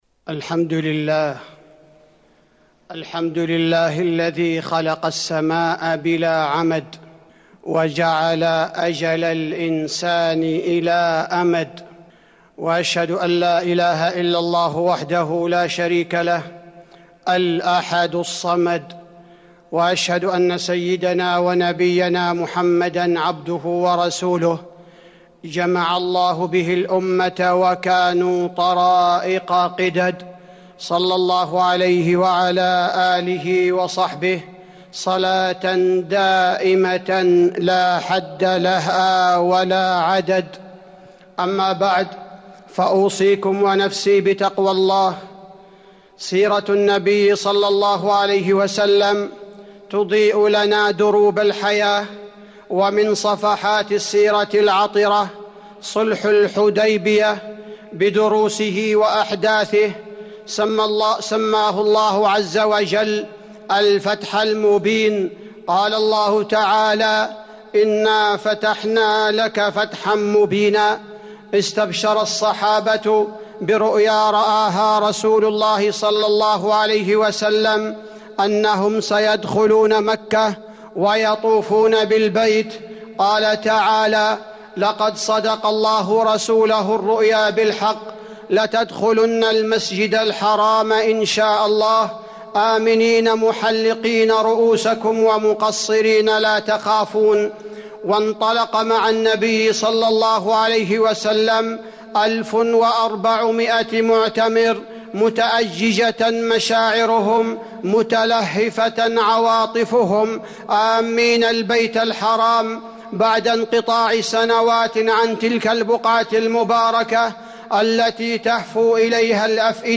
تاريخ النشر ١٩ ذو القعدة ١٤٤١ هـ المكان: المسجد النبوي الشيخ: فضيلة الشيخ عبدالباري الثبيتي فضيلة الشيخ عبدالباري الثبيتي دروس من صلح الحديبية في ظل جائحة كورونا